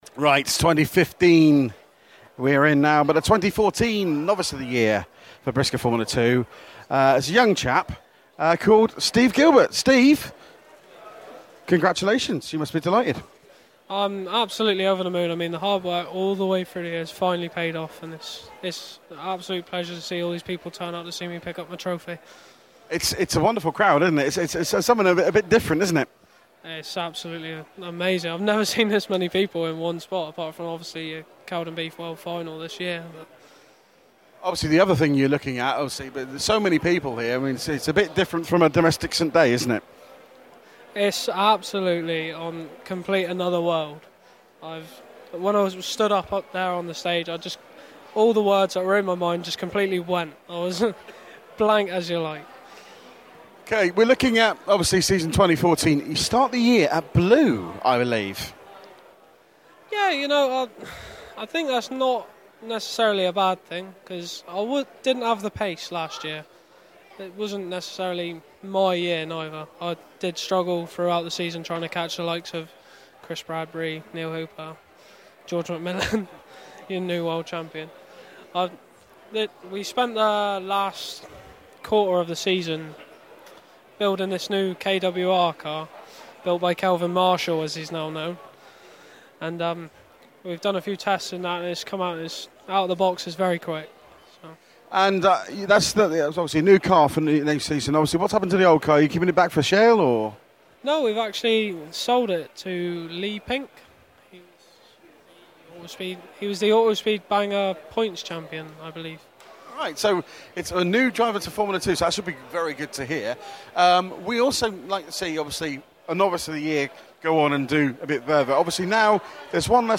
Autosport Show - Interview